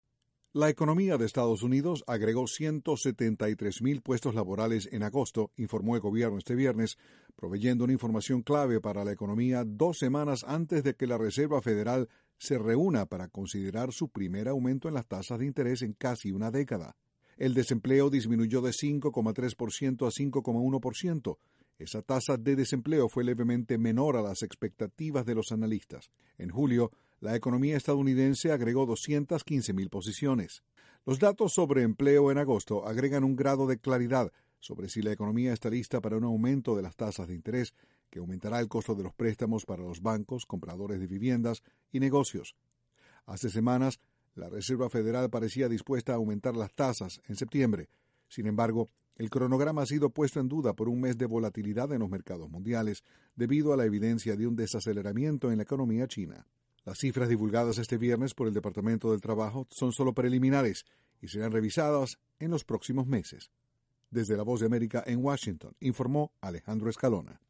Disminuye el desempleo en Estados Unidos. Desde la Voz de América, Washington, informa